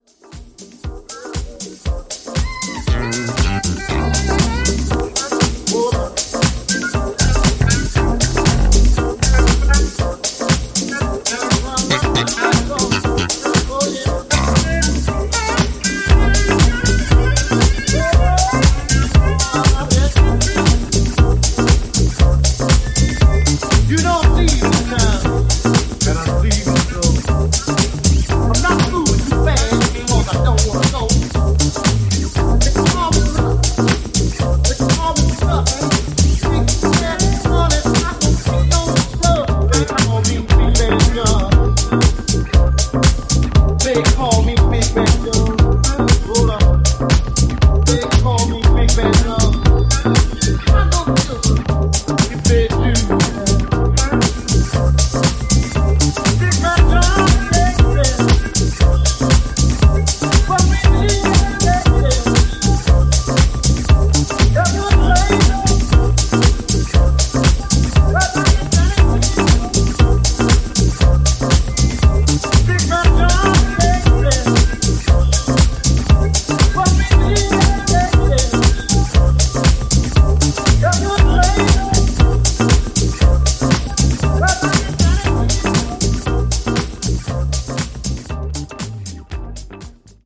ジャズファンク要素を取り入れながらモダンでウォームなビートダウン・ハウス路線を展開していく